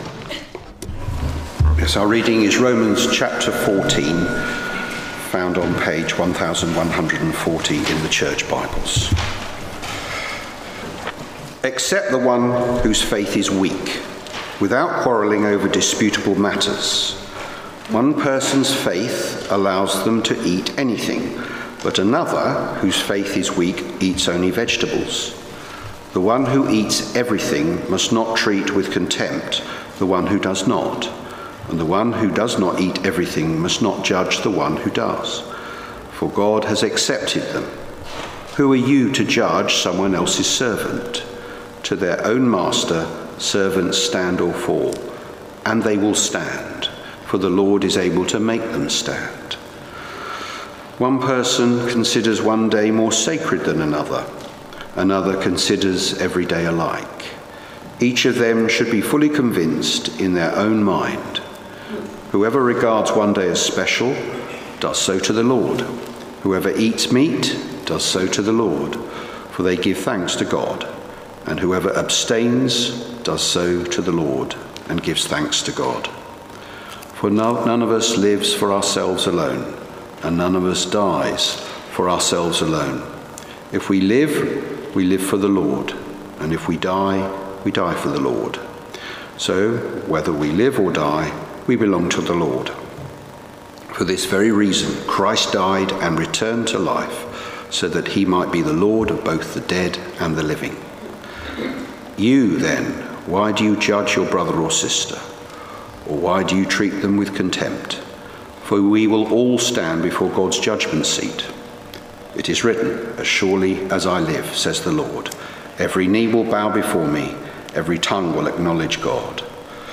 Romans 14 Service Type: Sunday Morning Sermon notes